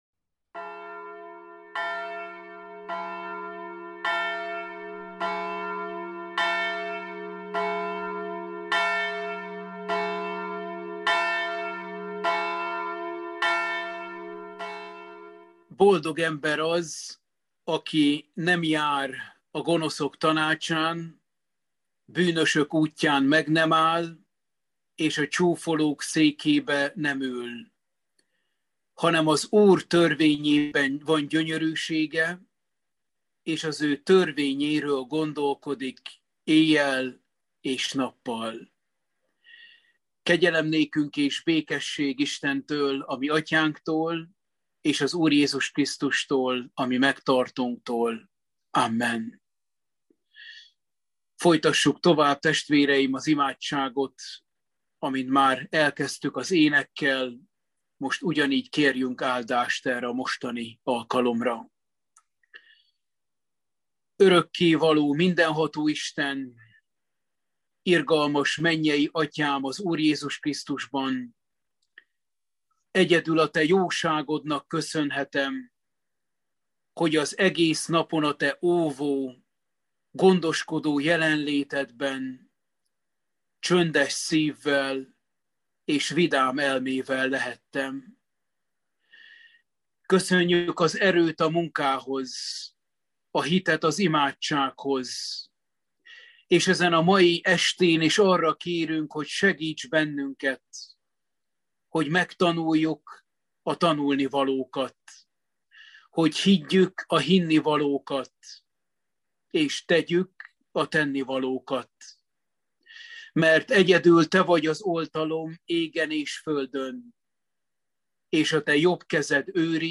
Római levél – Bibliaóra 11